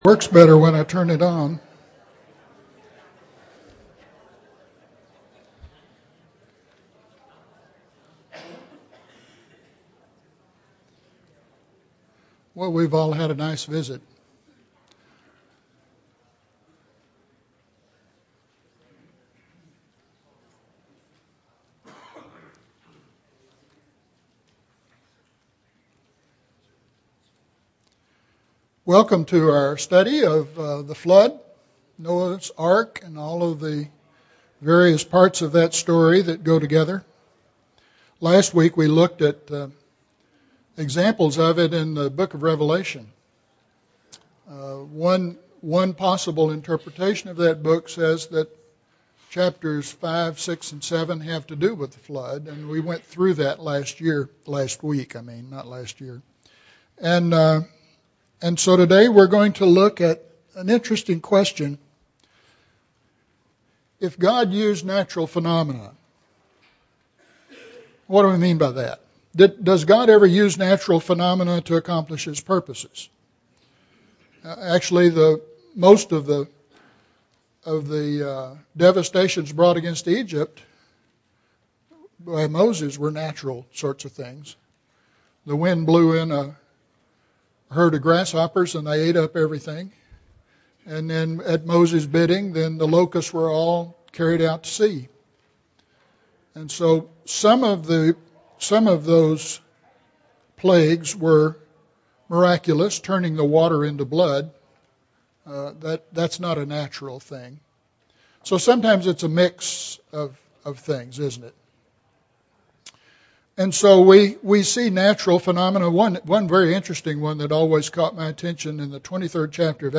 The Flood Mechanisms If God Used Natural Phenomenon (9 of 10) – Bible Lesson Recording
Sunday AM Bible Class